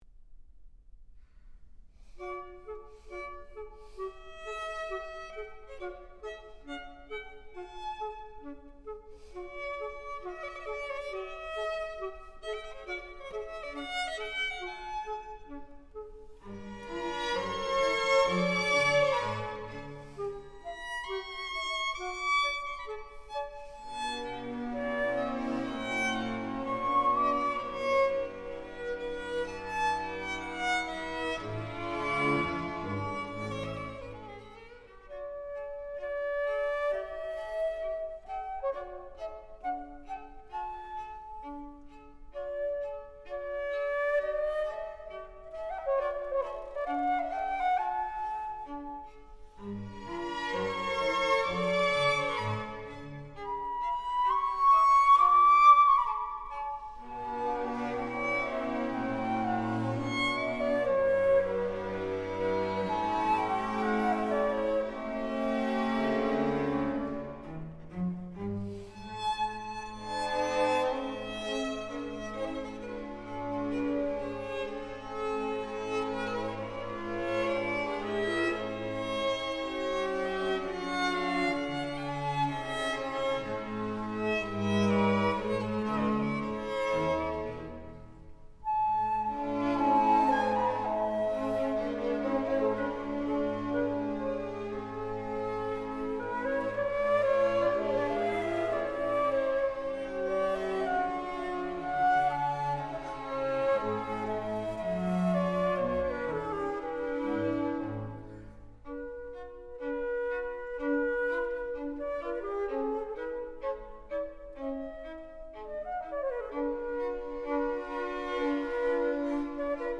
flûte, violon et orchestre – 2 Andante